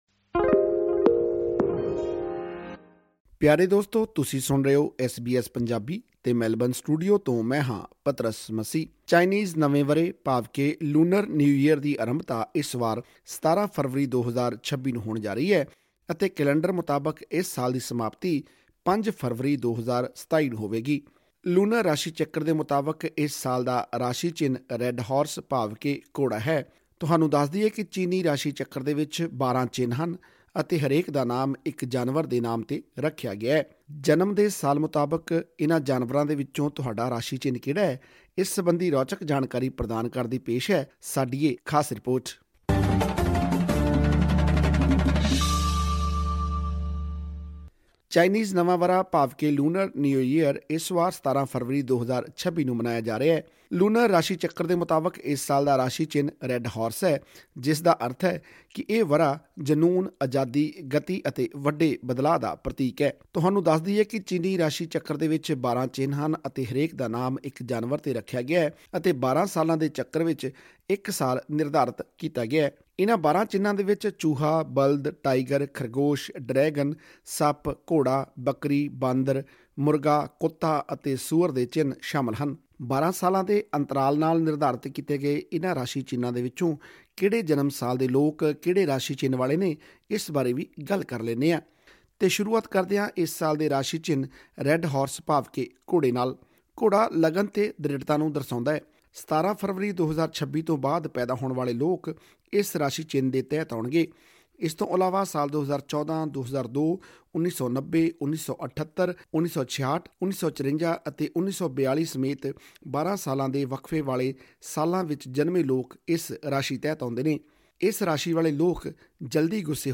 ਜਨਮ ਸਾਲ ਦੇ ਮੁਤਾਬਿਕ ਤੁਹਾਡਾ ਚਿੰਨ੍ਹ ਕਿਹੜਾ ਹੈ, ਇਸ ਸਬੰਧੀ ਰੌਚਕ ਜਾਣਕਾਰੀ ਹਾਸਿਲ ਕਰਨ ਲਈ ਸੁਣੋ ਇਹ ਆਡੀਓ ਰਿਪੋਰਟ